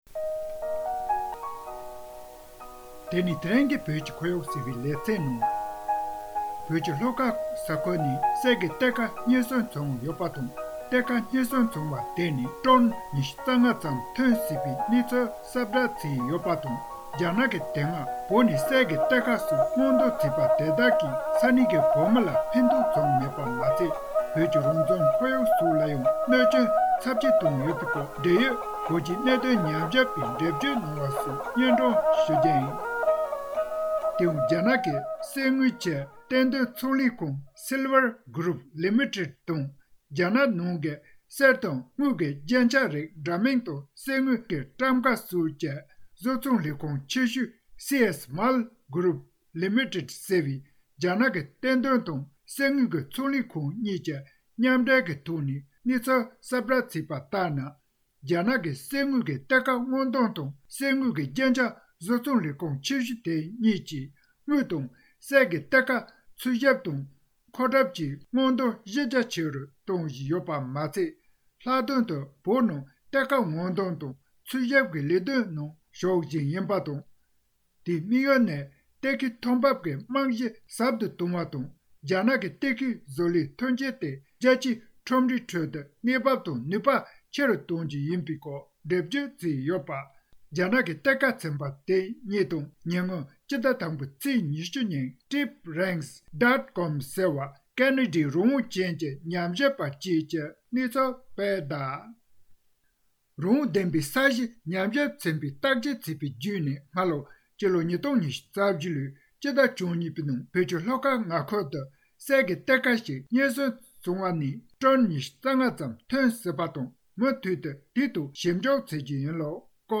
འབྲེལ་ཡོད་བོད་རྒྱའི་གནད་དོན་ཉམས་ཞིབ་པ་ལ་བཅའ་འདྲི་ཞུས་པ